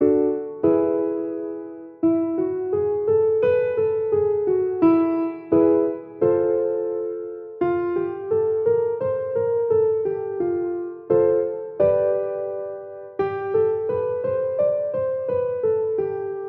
vocal-warm-up-1.mp3